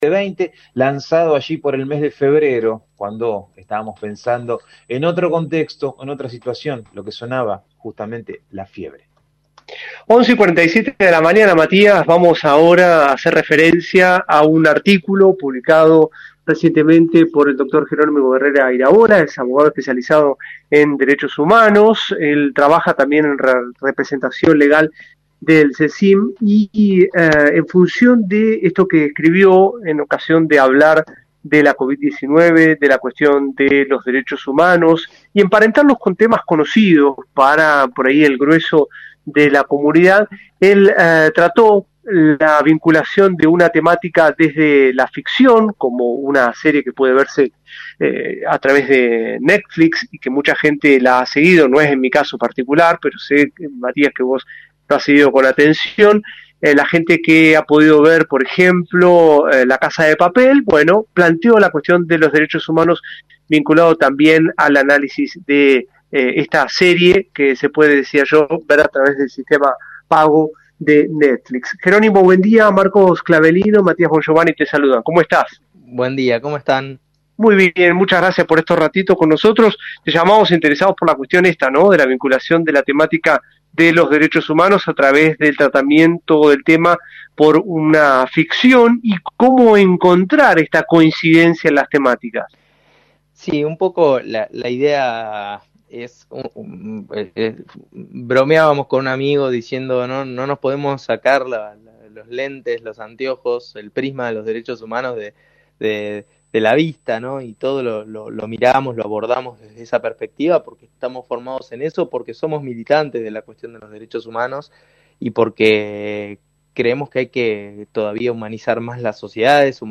charló hoy por la mañana con radio Universidad sobre su nota de opinión